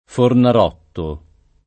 Fornarotto [ fornar 0 tto ] cogn.